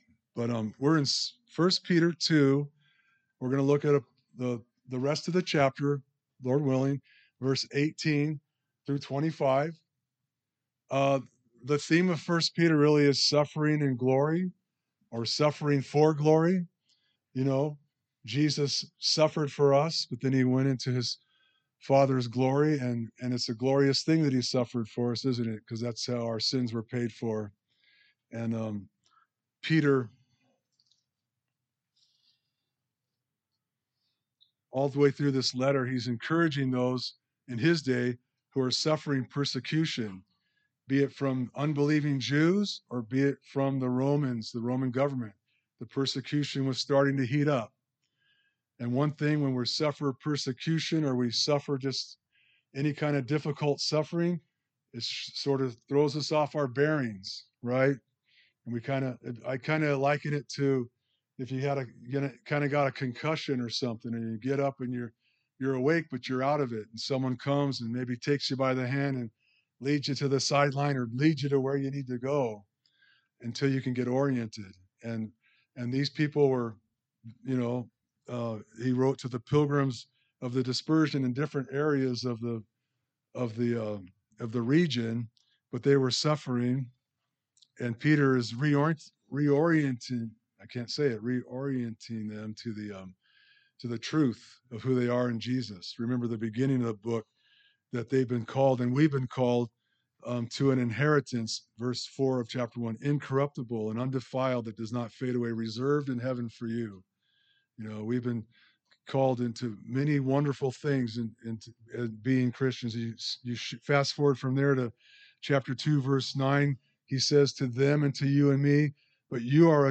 A message from the series "1 Peter."